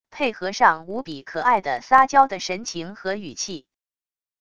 配合上无比可爱的撒娇的神情和语气wav音频